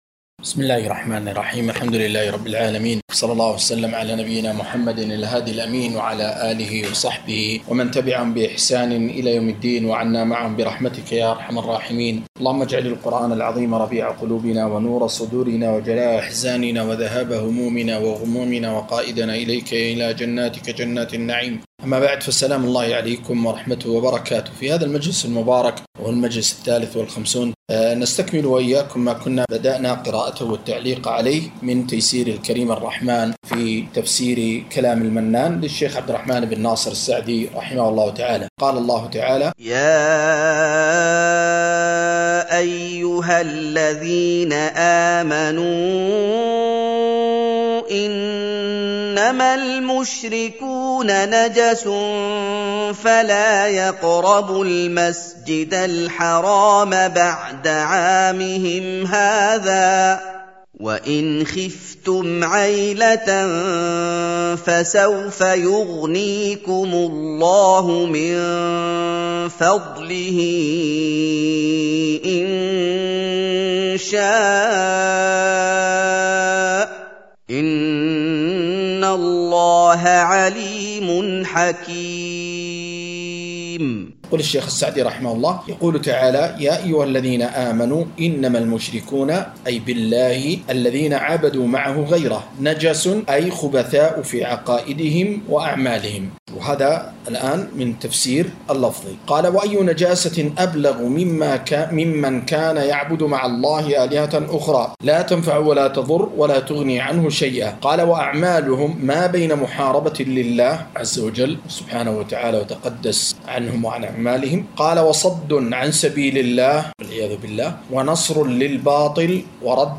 قراءة وتعليق